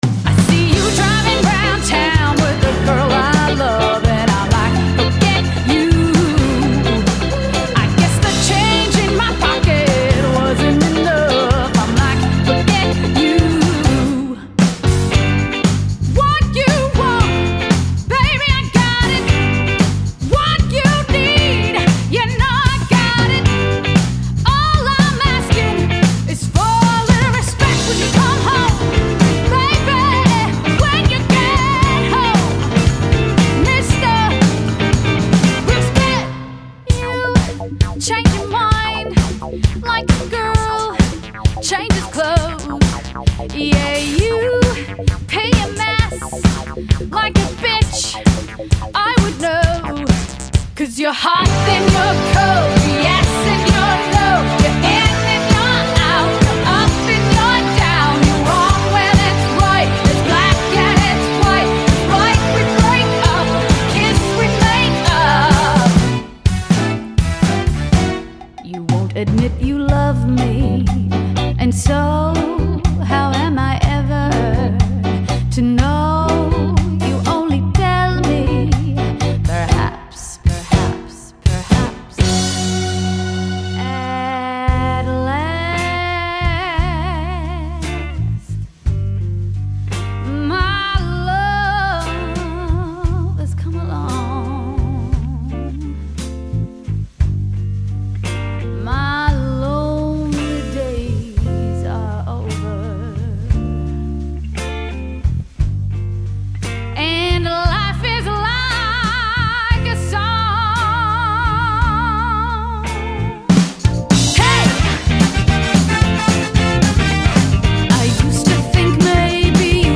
Lead Vocals
Guitar/Lead Vocals
Keyboards/Vocals
Drums/Vocals
Bass Guitar/Vocals